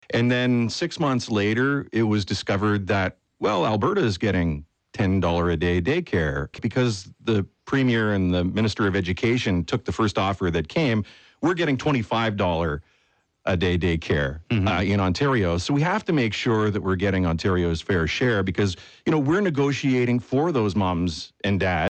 Smith describes what would happen if his government just accepted the federal offer as is.